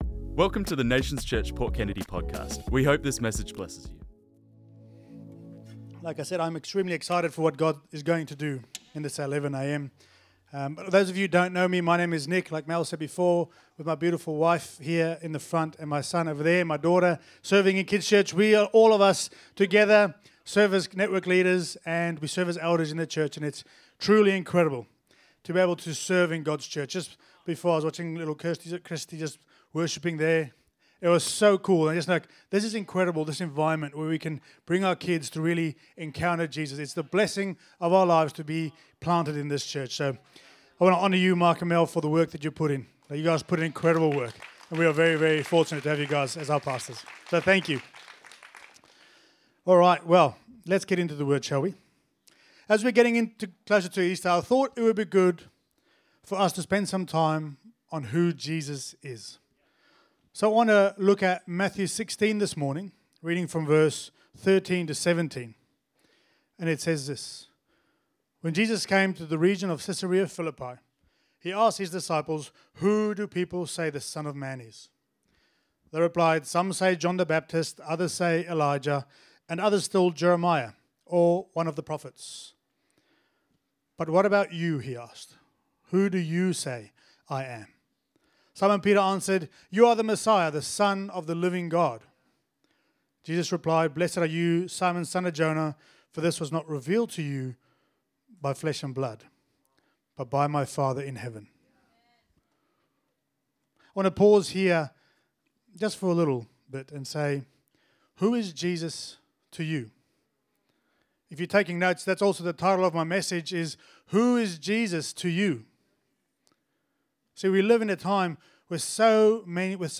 This message was preached on Sunday 29th March 2026